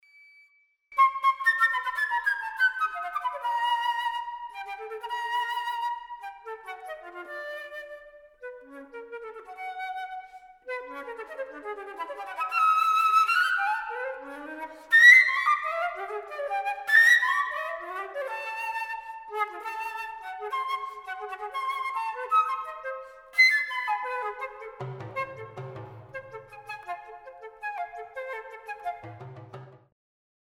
Flöte
Flute